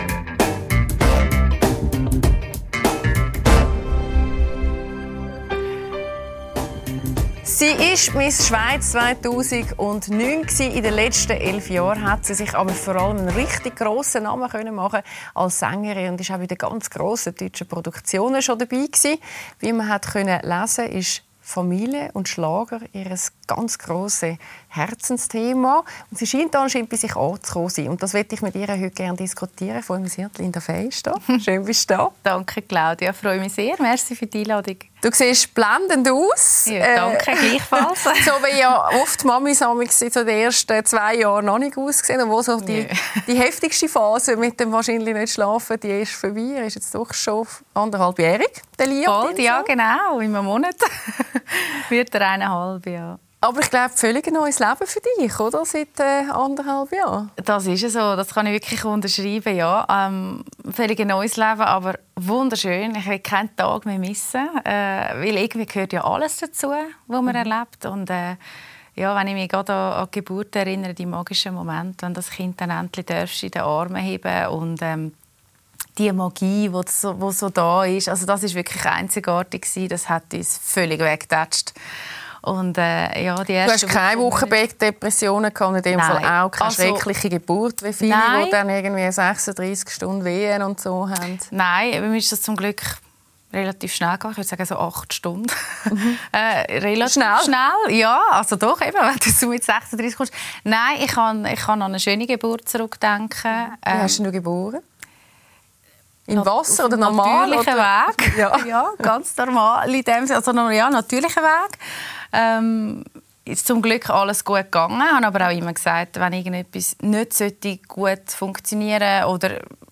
Mit Claudia Lässer spricht die Ex-Miss Schweiz über den Druck sich beweisen zu müssen und die Geburt ihres Sohnes.